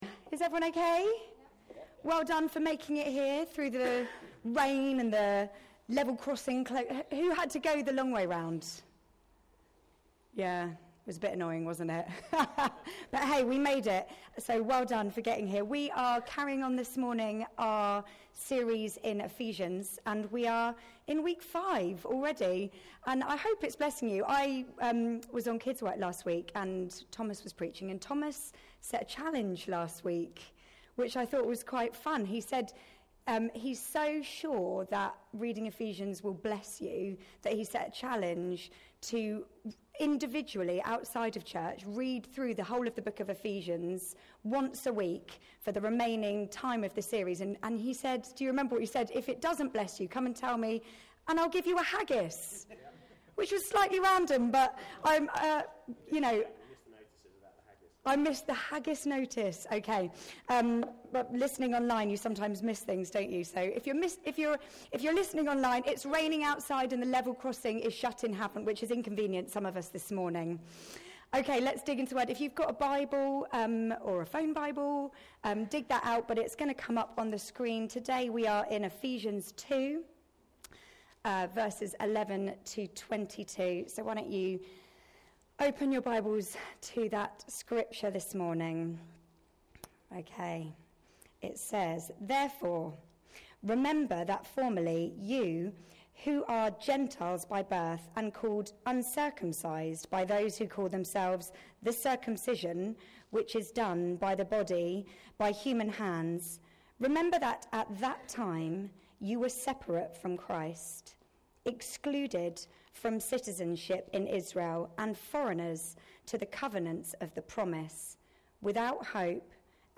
Grace Church Sunday Teaching